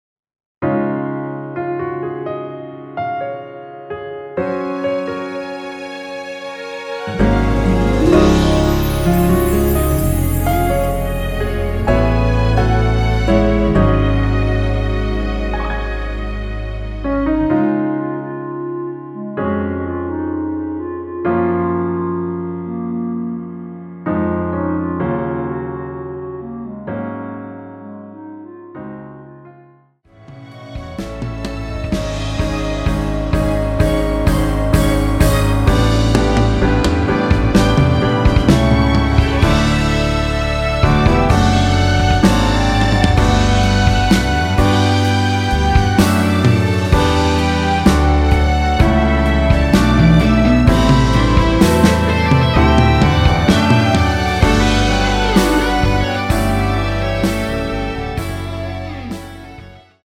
원키에서(-2)내린 멜로디 포함된 MR입니다.
Db
노래방에서 노래를 부르실때 노래 부분에 가이드 멜로디가 따라 나와서
앞부분30초, 뒷부분30초씩 편집해서 올려 드리고 있습니다.
중간에 음이 끈어지고 다시 나오는 이유는